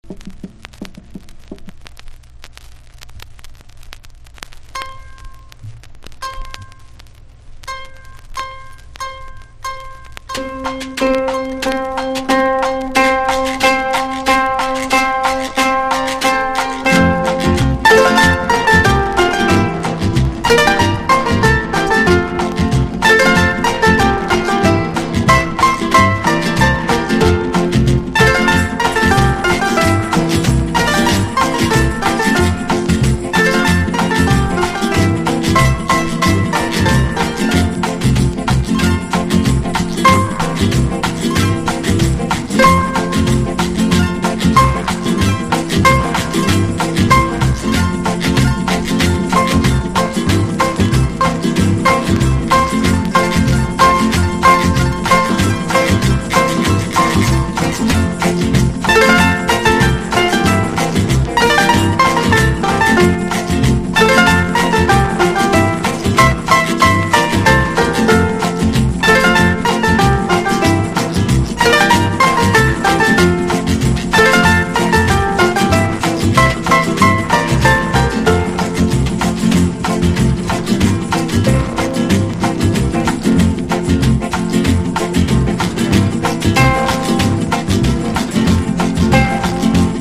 全編スタンダードなスペイン歌謡曲！